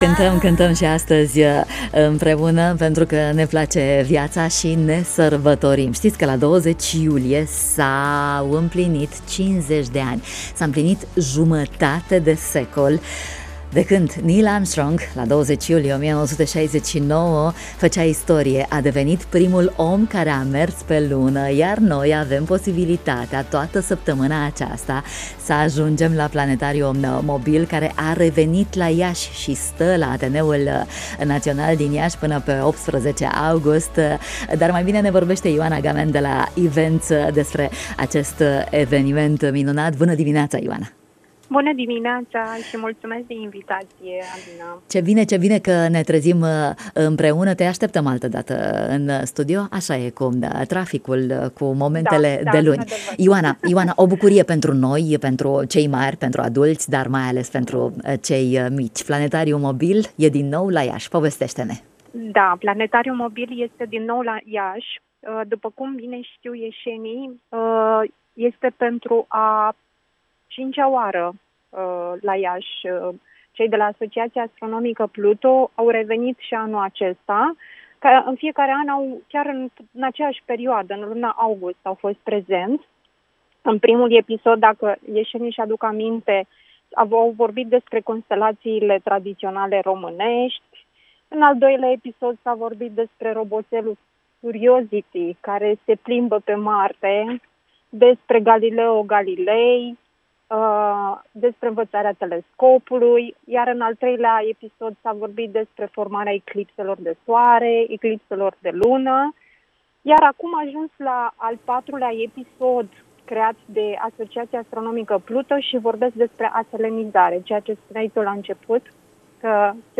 în matinalul Radio România Iaşi